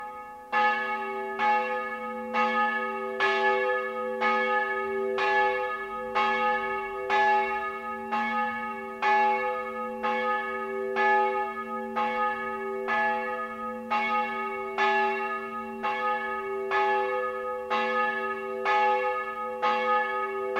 Josefs-Glocke
Josefsglocke.mp3